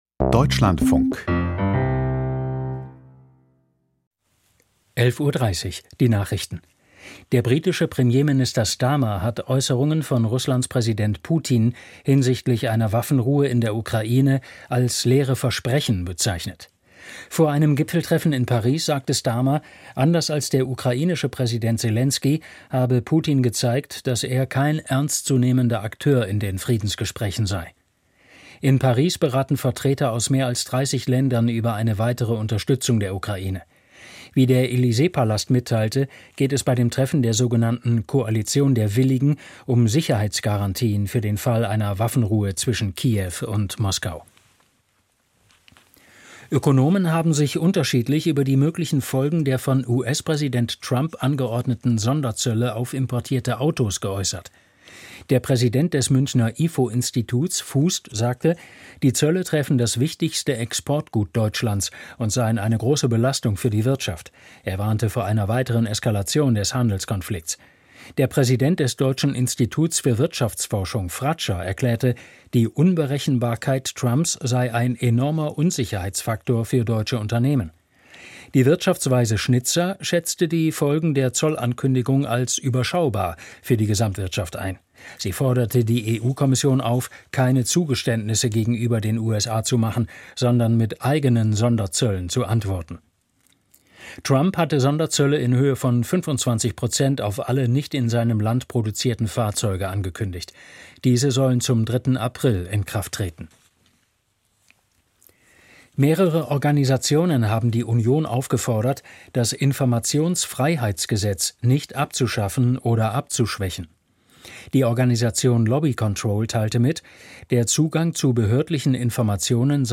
Die Deutschlandfunk-Nachrichten vom 12.01.2025, 21:00 Uhr.